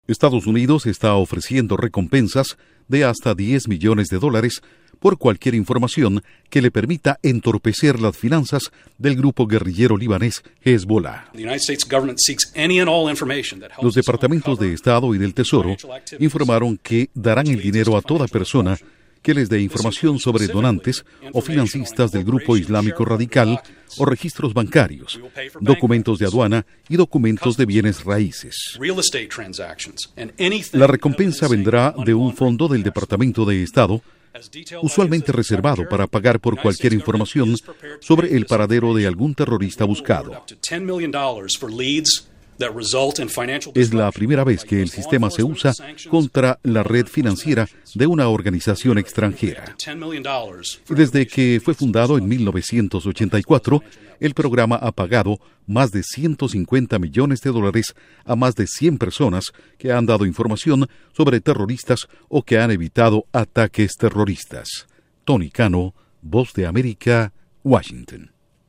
Duración: 1:08 Con audios del Departamento de Estado